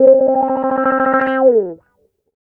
Track 13 - Clean Guitar Wah 01.wav